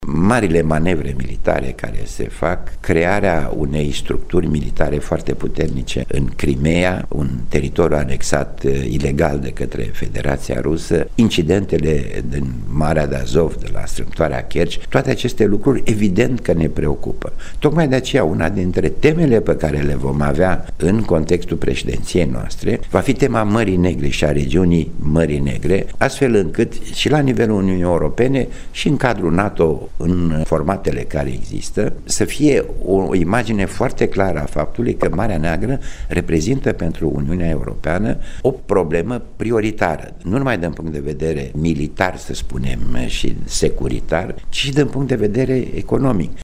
Una dintre temele majore ale preşedinţiei României la Consiliul Uniunii Europene va fi centrată pe importanţa regiunii Mării Negre, a declarat şeful diplomaţiei române, Teodor Meleşcanu, într-un interviu pentru Radio România. Din păcate, a mai spus ministrul de externe, preocuparea pregnantă din ultima perioadă, este creşterea prezenţei militare a Rusiei în Marea Neagră şi la frontierele de est ale Uniunii Europene şi ale NATO.